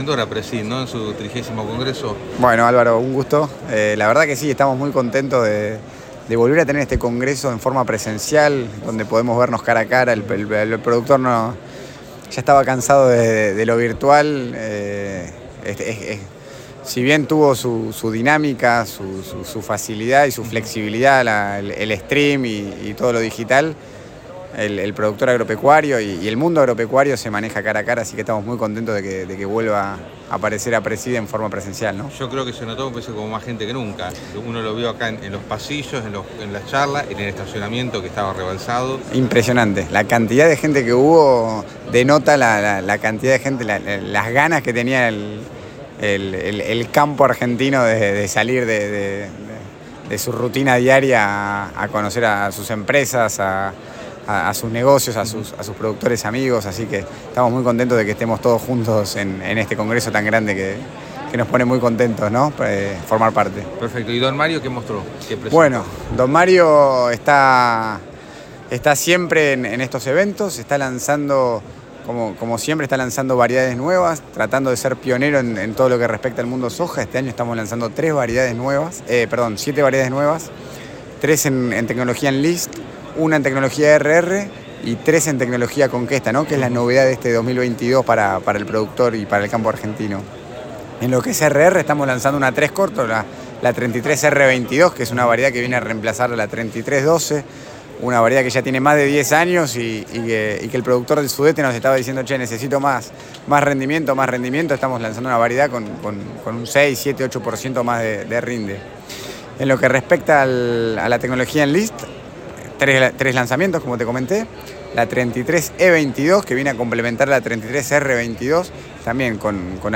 en diálogo con El Campo Hoy remarcó